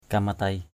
/ka-ma-teɪ/ (d.) trụ bườm = mât. mast. geng kamatei ahaok g$ kmt] a_h<K trụ bườm con tàu = id. mast.